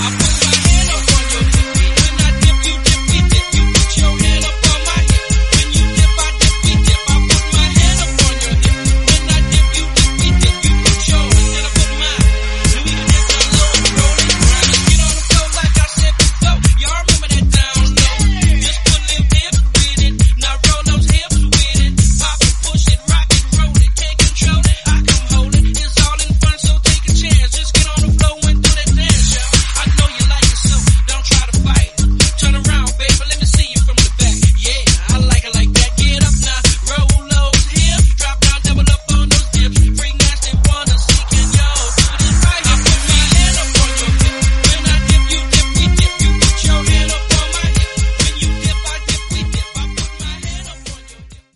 Dj Intro Outro
Genres: 2000's , FUTURE HOUSE , RE-DRUM
Clean BPM: 126 Time